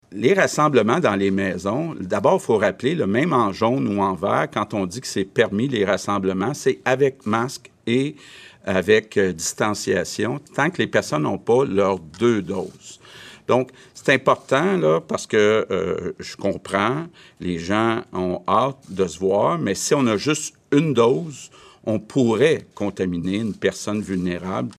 Mais le premier ministre François Legault recommande la prudence.
CUT_LEGAULT_PRUDENCE.mp3